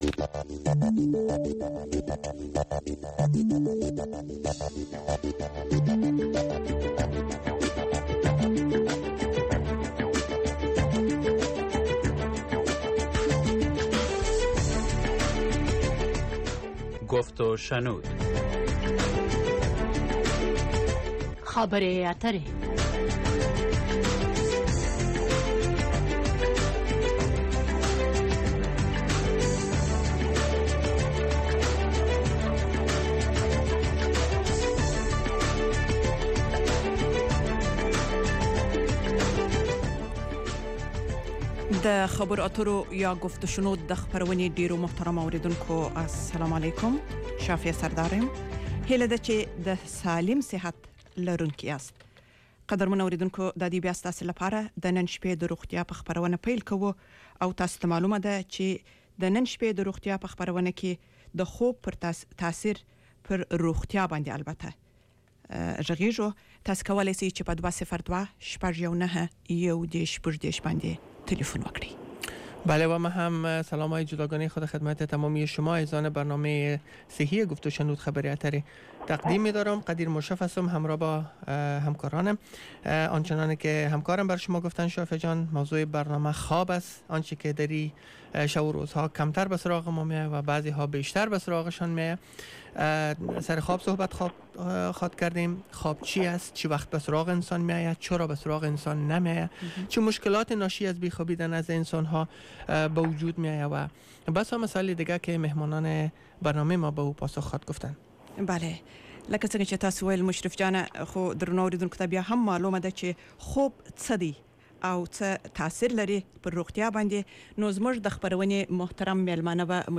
The URL has been copied to your clipboard No media source currently available 0:00 1:00:00 0:00 لینک دانلود | ام‌پی ۳ برای شنیدن مصاحبه در صفحۀ جداگانه اینجا کلیک کنید